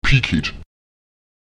Lautsprecher piket [Èpiket] das Auge (das Sehorgan)